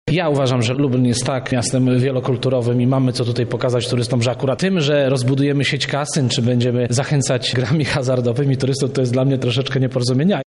– mówi radny Zbigniew Ławniczak.